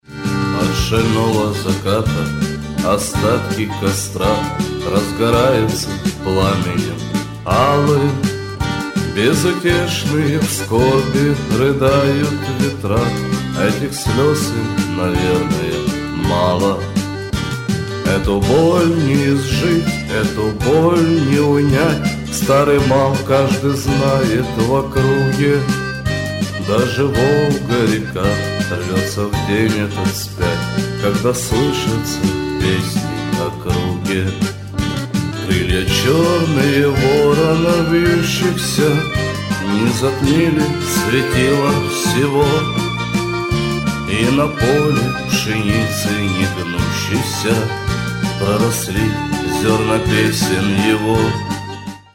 • Качество: 128, Stereo
душевные
грустные
спокойные
шансон